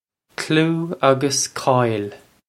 kloo uggus koil
This is an approximate phonetic pronunciation of the phrase.